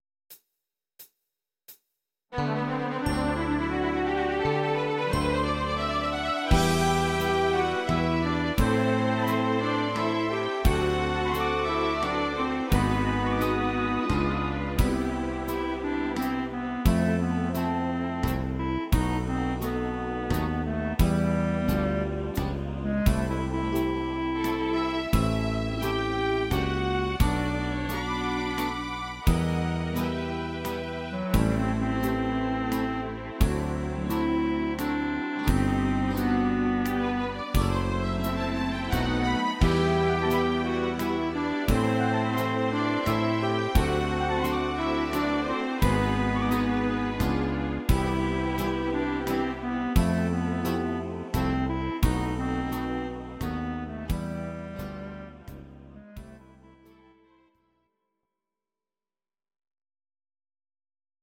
These are MP3 versions of our MIDI file catalogue.
Please note: no vocals and no karaoke included.
slow waltz version